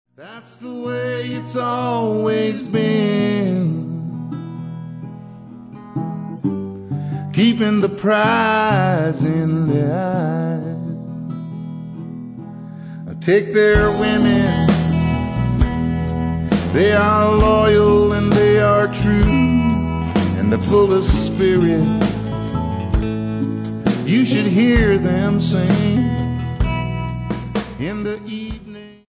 lead vocals, guitar, dulcimer
Recorded at General Store Recording